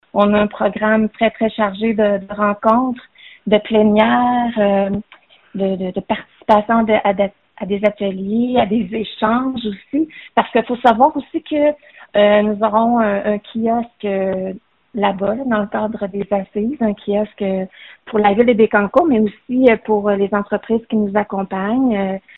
La mairesse a expliqué qu’ils seront très occupés lors de leur séjour d’une semaine.